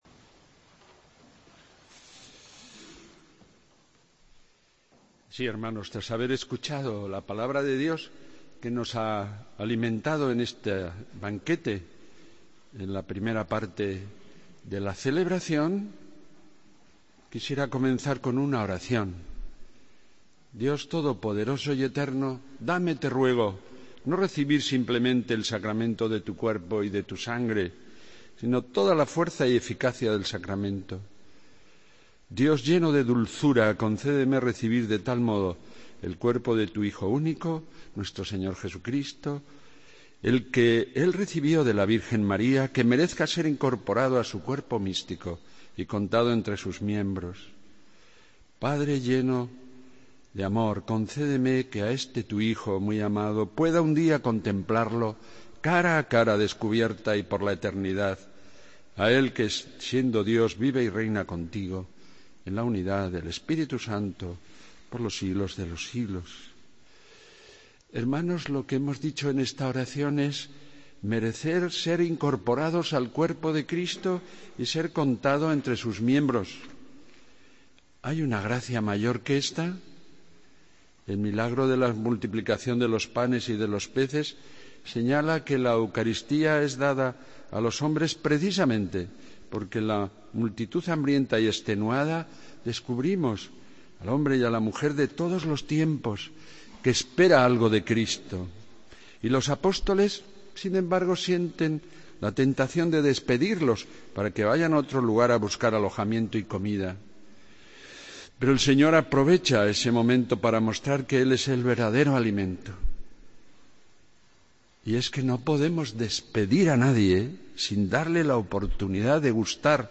Homilía del domingo 26 de junio de 2016